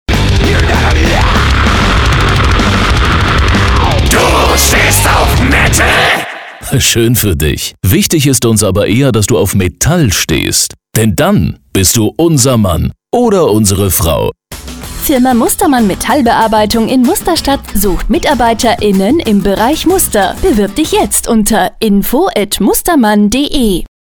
KW41 - Recruiting Funkspot
Demospot-Recruiting.mp3